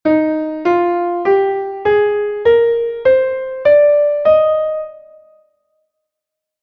Dis-Dur-Tonleiter.mp3